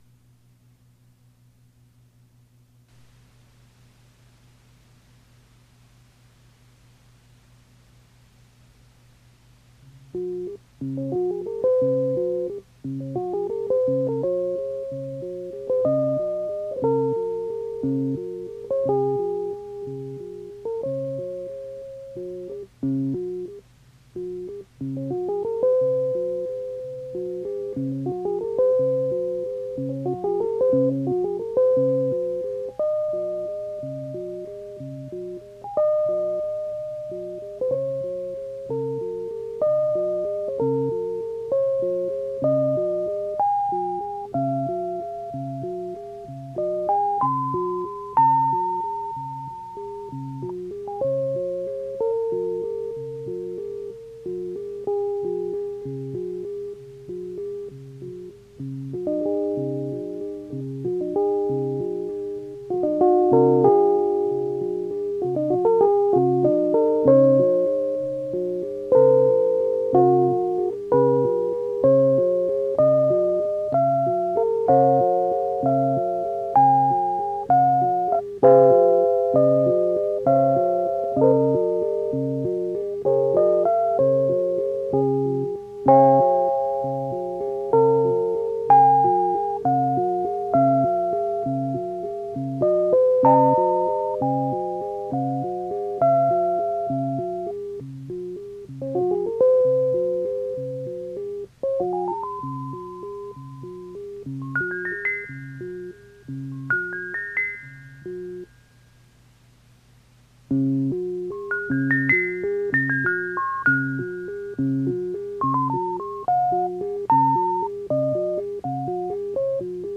ハンマーが金属を叩く構造で、水玉が転がるような音が
その当時のエレピアンの演奏を録音したものを
ラジカセで録音した物です。
（即興演奏）